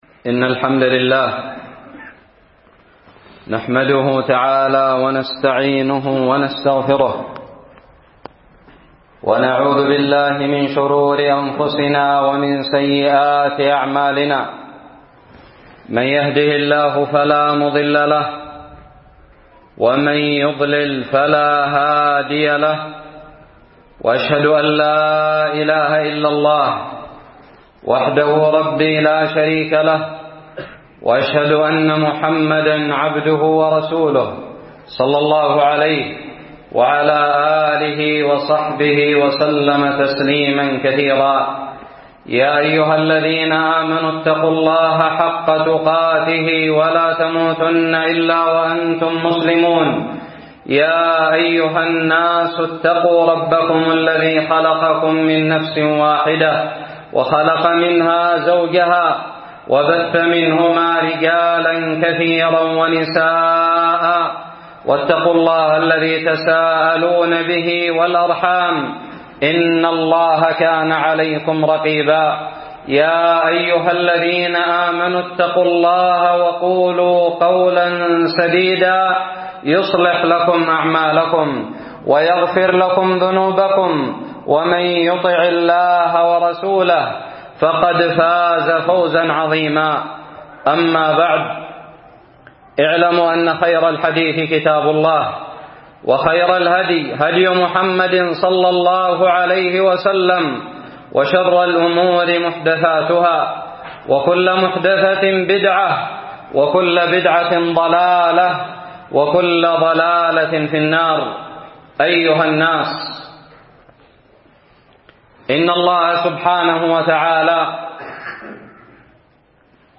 خطب الجمعة
ألقيت بدار الحديث السلفية للعلوم الشرعية بالضالع في 11 جمادى الآخرة 1438هــ